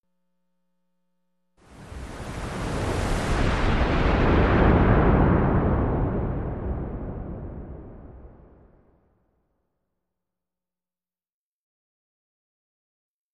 Sports Wave Whoosh
Arena Crowd; Wave Whoosh For Crowd Transition.